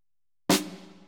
Snare1.wav